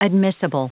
Prononciation du mot admissible en anglais (fichier audio)
Prononciation du mot : admissible